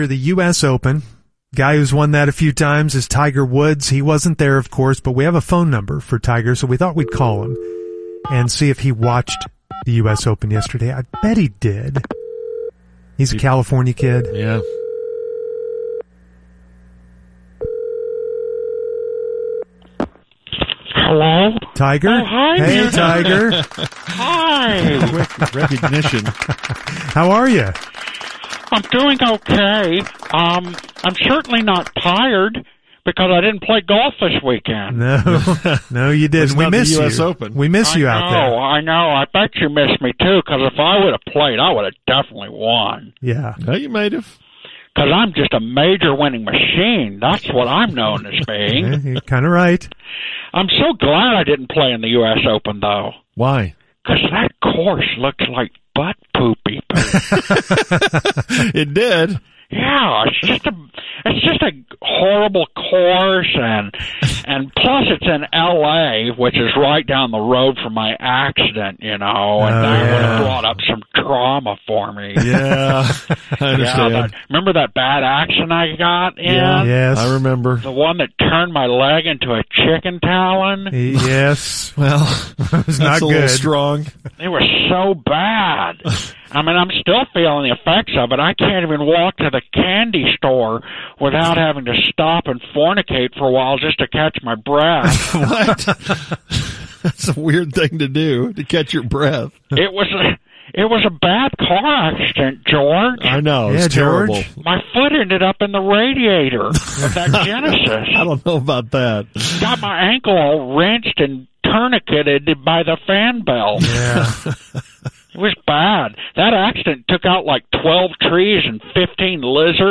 Fake Tiger on the US Open 2023 - The Musers 6.19.2023 - The UnTicket